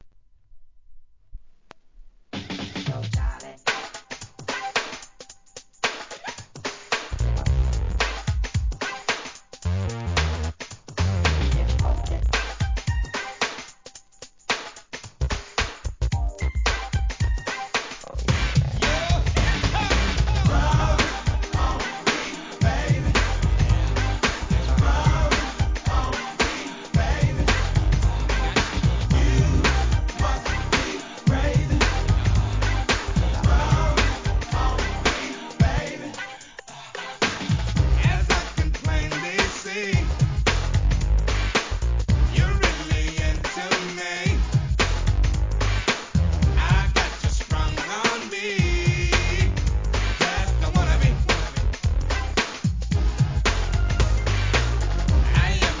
HIP HOP/R&B
ファンキーで引き締まったビートのNEW JACK隠れた名曲!!!!